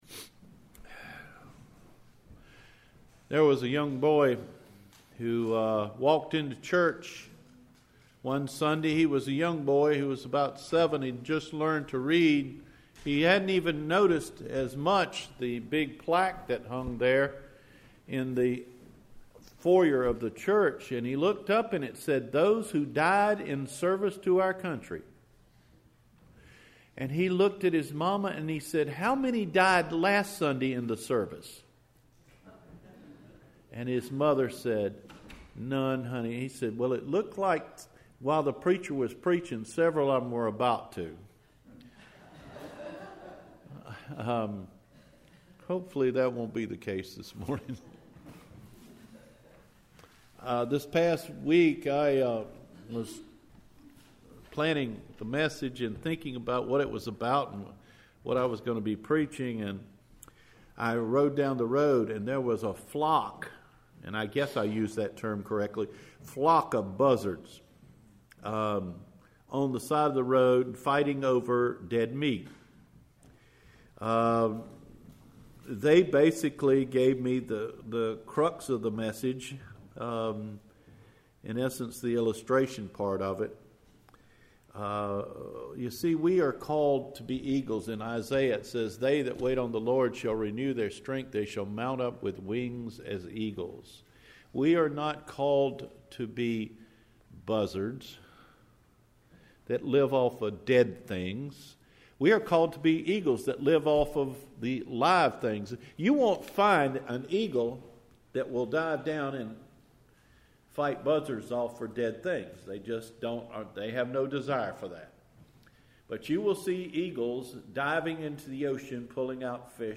Eagles and Buzzard – March 11 Recorded Sermon